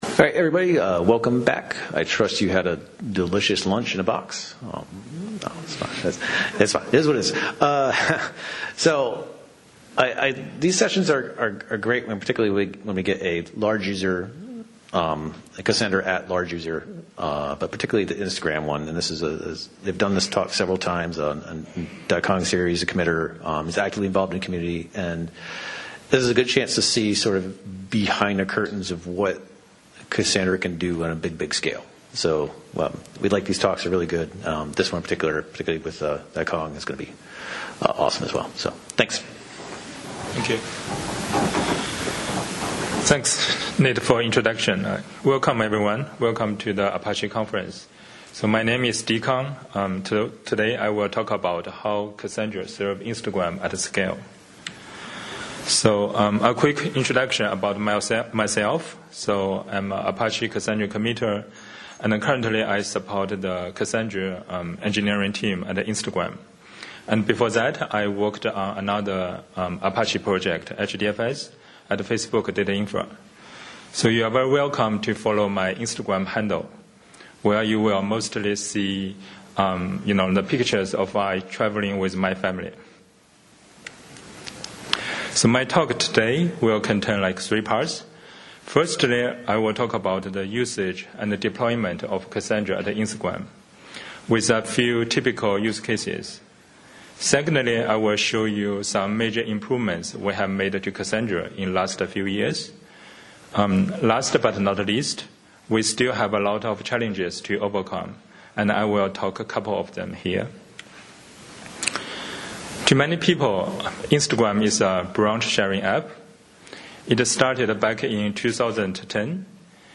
We use Cassandra behind a lot of different product features. In this presentation, I will talk about how we use Cassandra to serve our critical use cases; the improvements we made in last several years to make sure Cassandra can meet our low latency, high scalability requirements; and future plans.